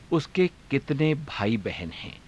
ゆっくり